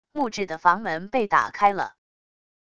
木质的房门被打开了wav音频